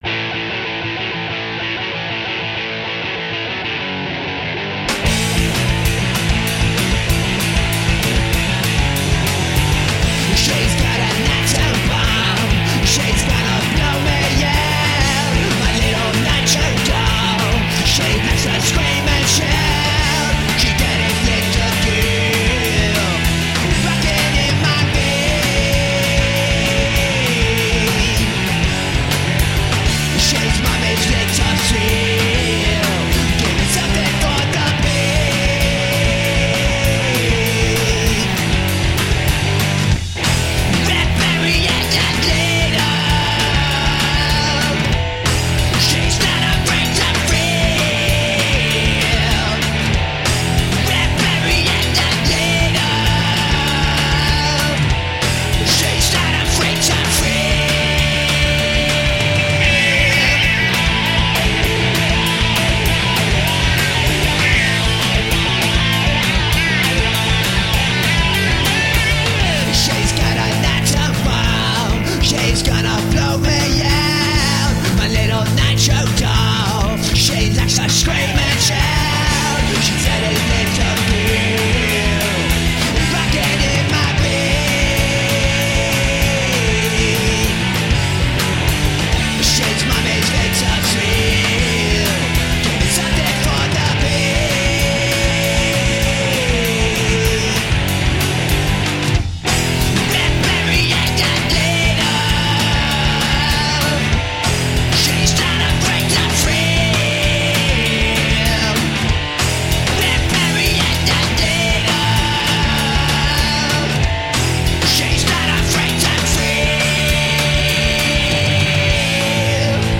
High energy rock and roll.
Tagged as: Hard Rock, Metal, Punk, High Energy Rock and Roll